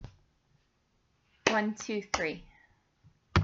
Creating a Rhythm with Body Percussion
3 — Clap, shoulder tap, shoulder tap